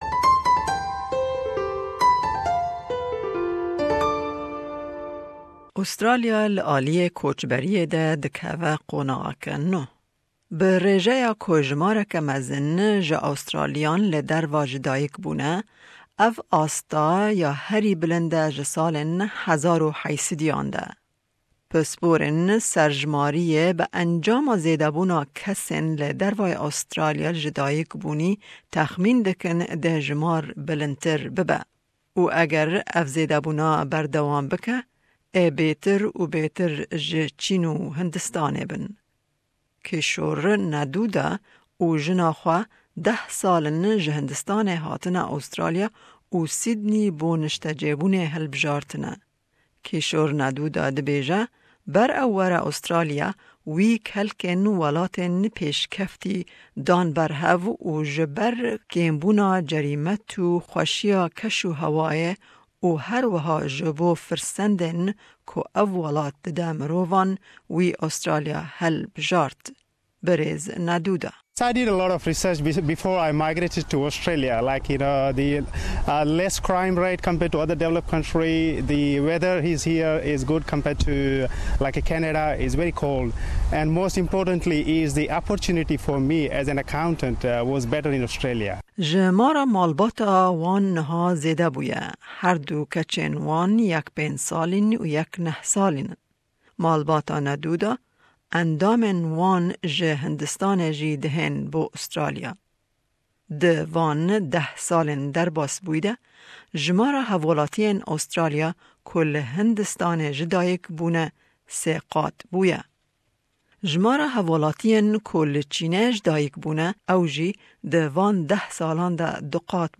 Raport bi Îngilîzî û Kurdî.